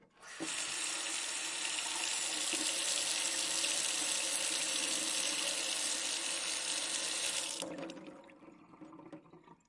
sink rag
描述：Running water from a faucet, turns off and a wet rag is squeezed dry.
标签： drain faucet rag sink squeeze water
声道立体声